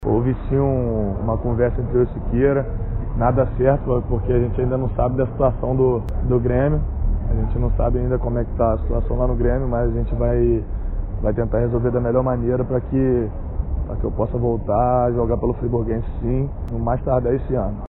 Nossa equipe de reportagem conseguiu com exclusividade uma entrevista com o jogador que manifestou interesse em retorna aos gramados.